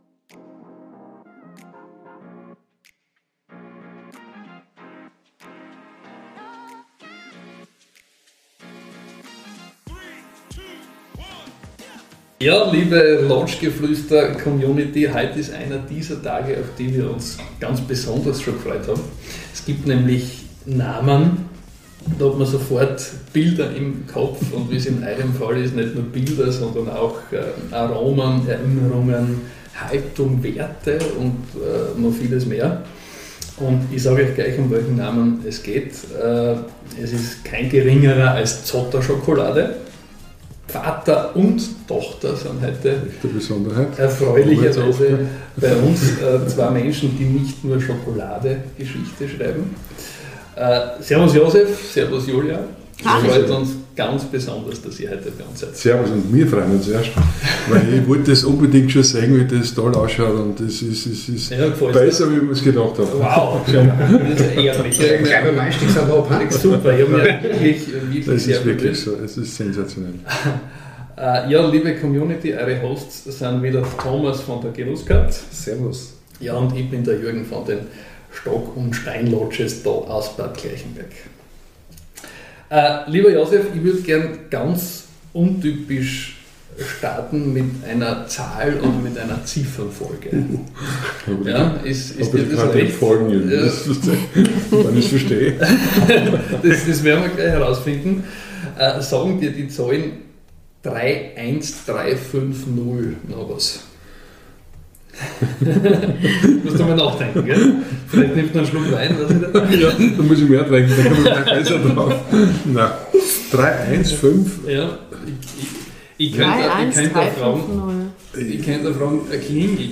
Diese Episode wurde aufgenommen in den traumhaft gelegenen Stock & Stein Lodges, wo sich gute Gespräche und guter Geschmack auf ganz besondere Weise treffen.